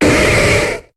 Cri de Mackogneur dans Pokémon HOME.